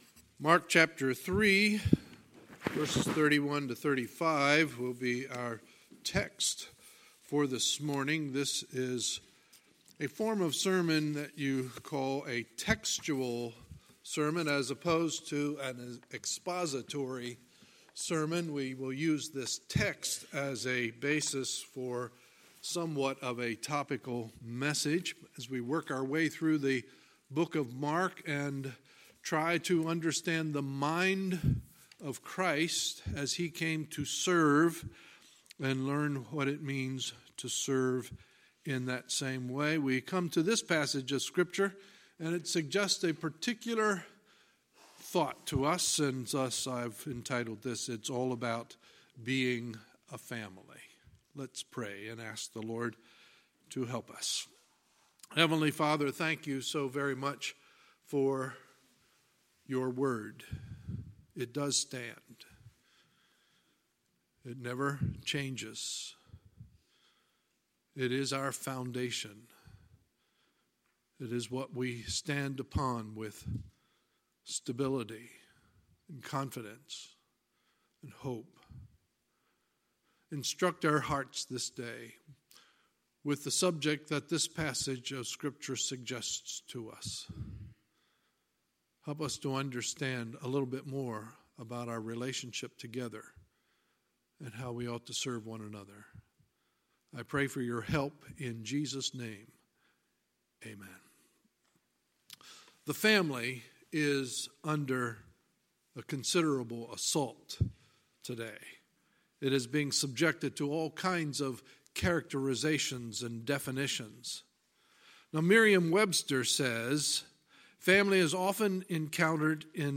Sunday, March 24, 2019 – Sunday Morning Service